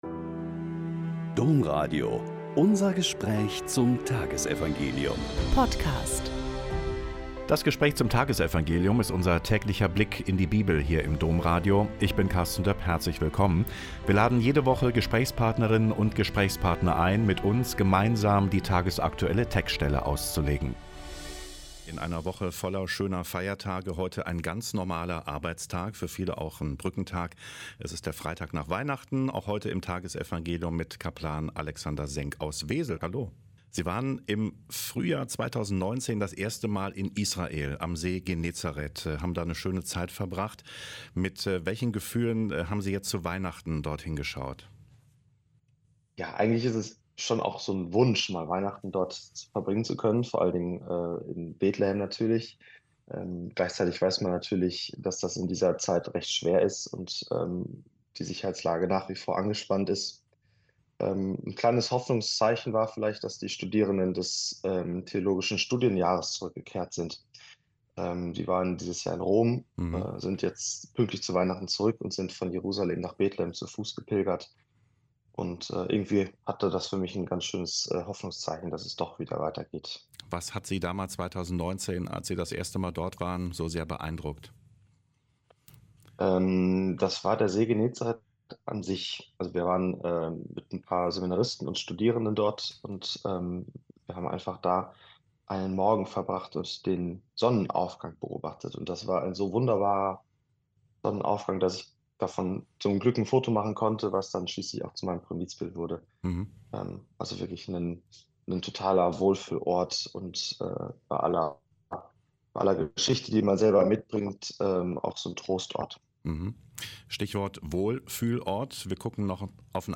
Joh 20,2-8 - Gespräch